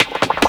FILLSNARE1-L.wav